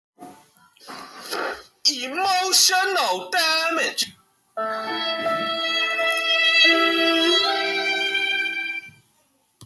Sad Violin And Emotional Damag Sound Button - Free Download & Play